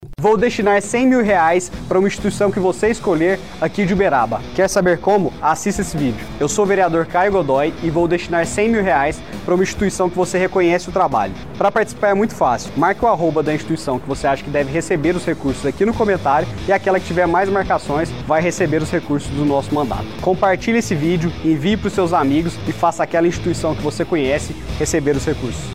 Durante a reunião, foi apresentado um vídeo onde um vereador de Uberaba, no Triângulo Mineiro, abre votação popular na internet para destinação dos recursos a que ele teria direito.
Clique e Ouça Vereador de Uberaba